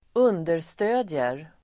Uttal: [²'un:der_stö:d(j)er]